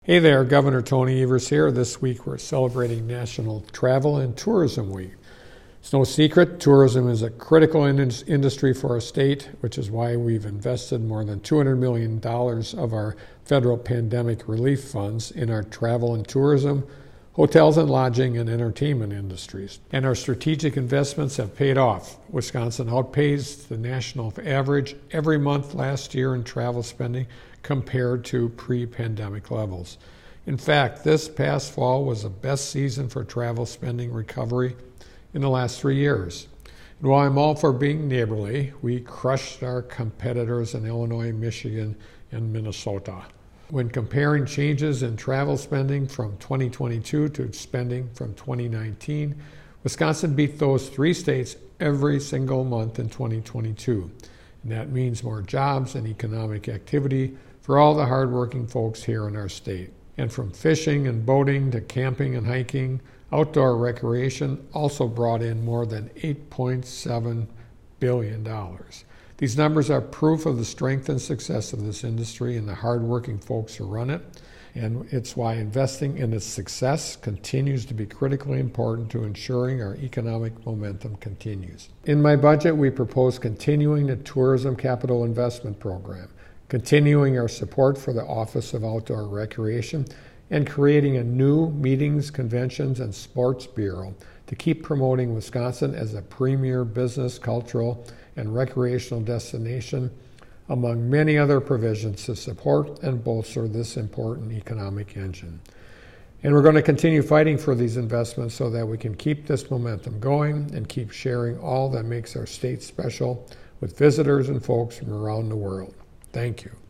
Weekly Dem radio address: Gov. Evers on plan to bolster Wisconsin's tourism industry - WisPolitics
MADISON — Gov. Tony Evers today, during National Travel and Tourism Week, delivered the Democratic Radio Address highlighting his plan to bolster and continue the success of Wisconsin’s tourism industry, including outdoor recreation.